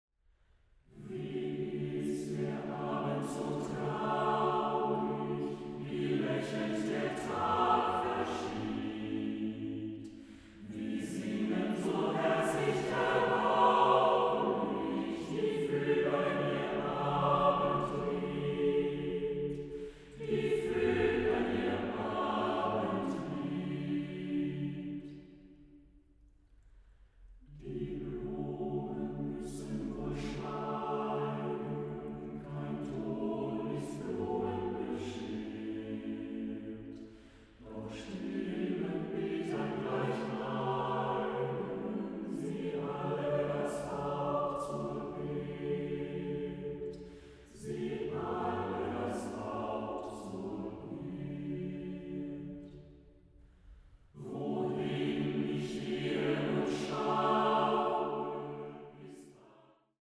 Chamber choir